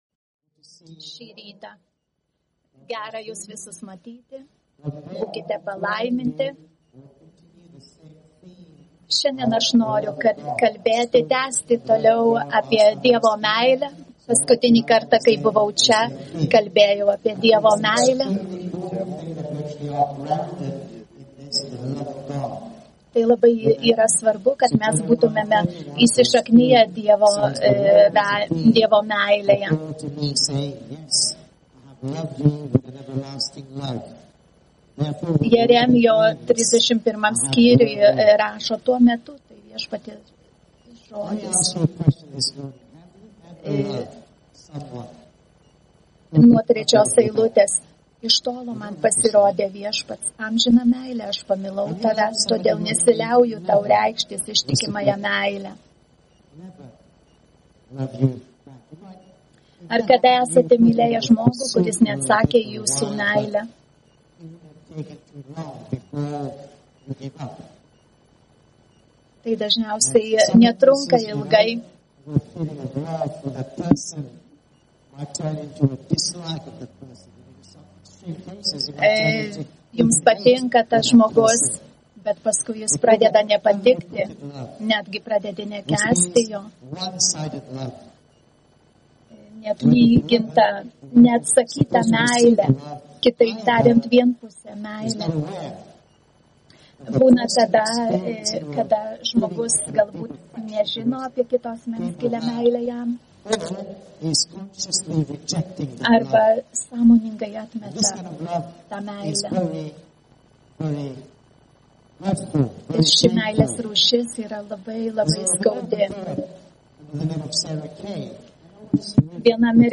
PAMOKSLO ĮRAŠAS MP3